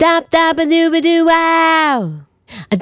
Dop Daba Doo Wow 085-E.wav